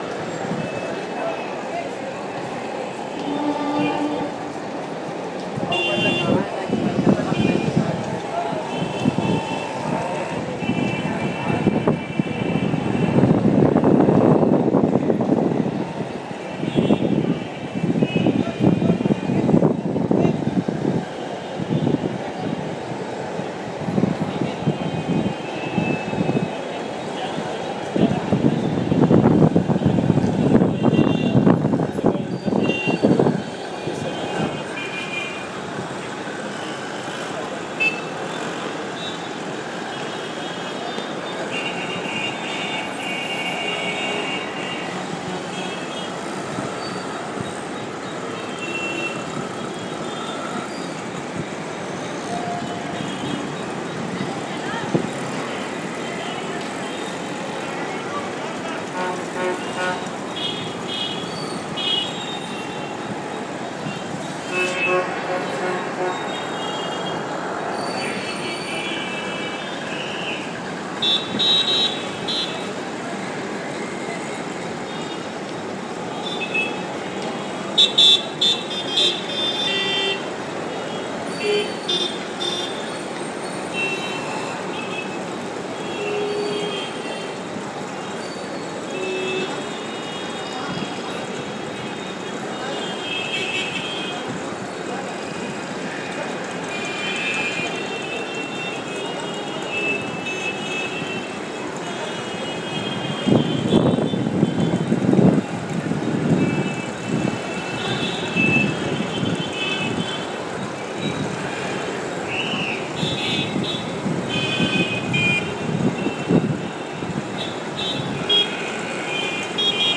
Bandra Station from Skywalk